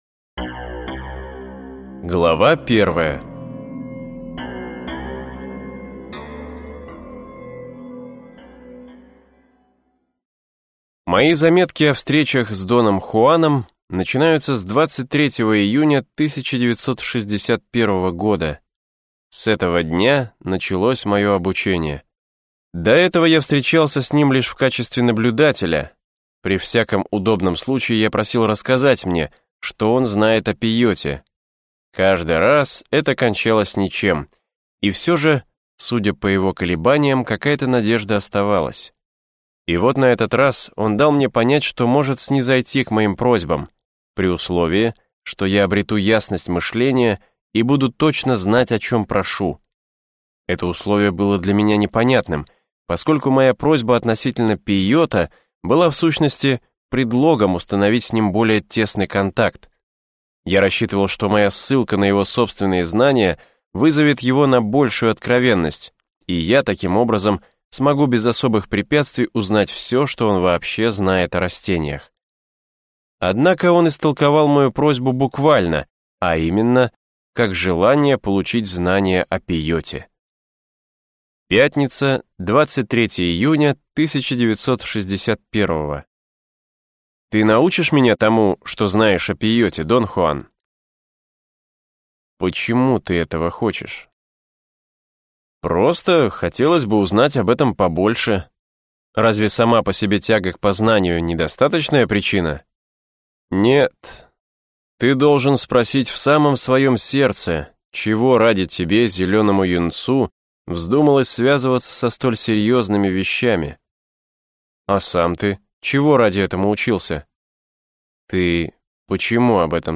Аудиокнига Учение дона Хуана - купить, скачать и слушать онлайн | КнигоПоиск